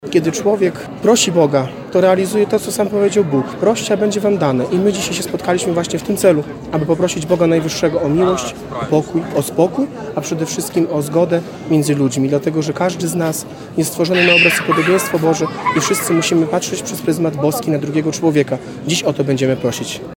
Wzruszenie i przejęcie na twarzach Ukrainek, które brały udział we wczorajszej modlitwie ekumenicznej na Rynku w Bielsku-Białej.